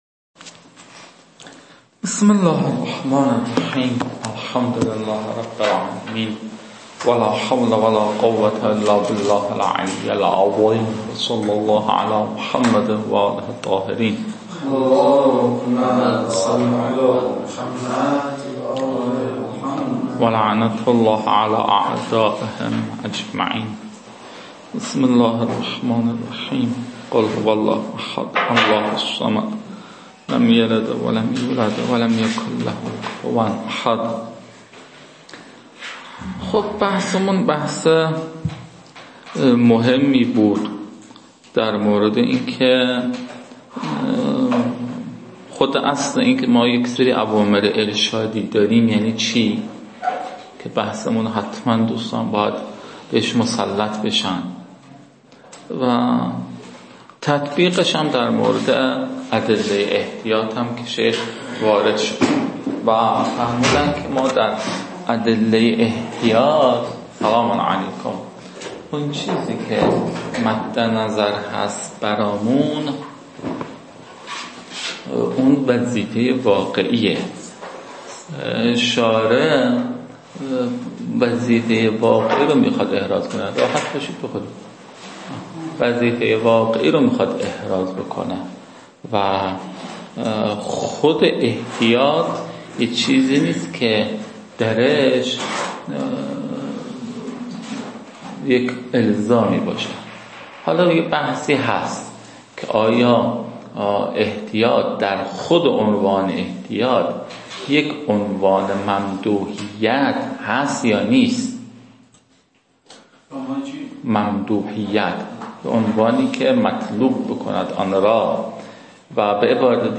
این فایل ها مربوط به تدریس مبحث برائت از كتاب فرائد الاصول (رسائل) متعلق به شیخ اعظم انصاری رحمه الله می باشد